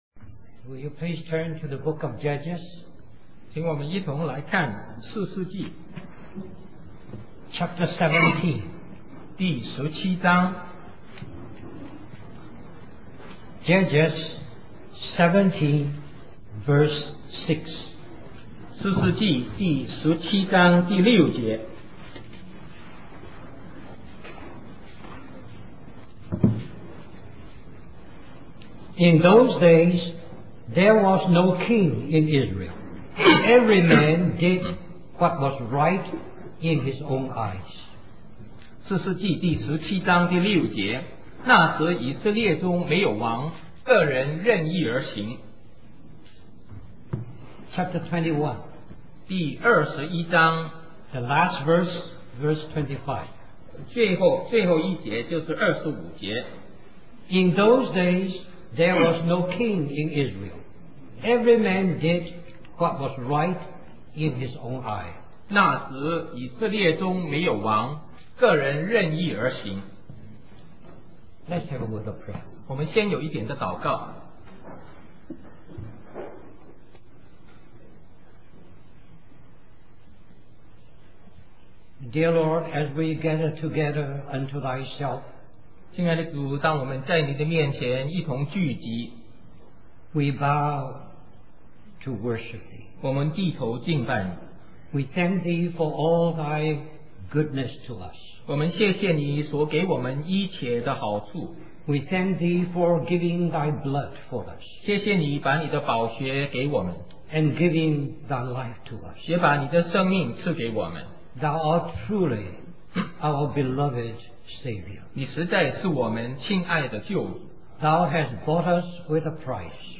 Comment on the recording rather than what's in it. This message contains both English and Chinese.